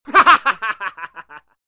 Laugh.wav